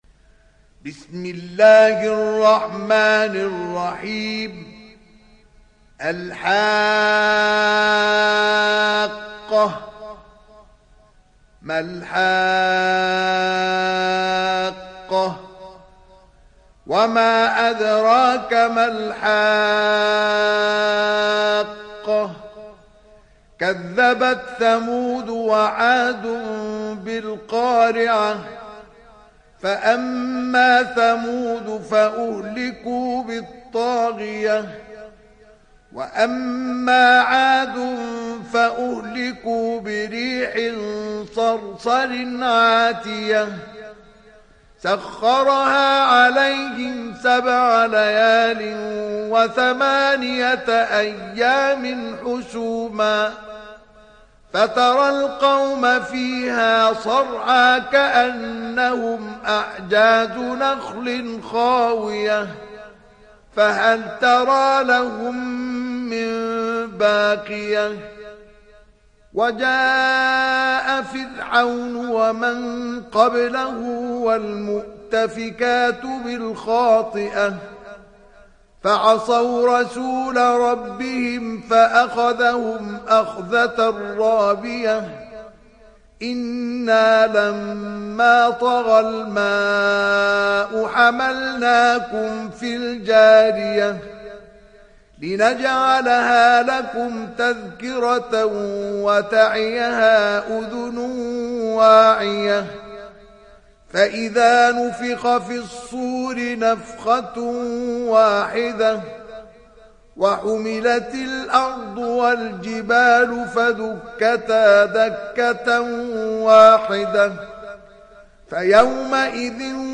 تحميل سورة الحاقة mp3 بصوت مصطفى إسماعيل برواية حفص عن عاصم, تحميل استماع القرآن الكريم على الجوال mp3 كاملا بروابط مباشرة وسريعة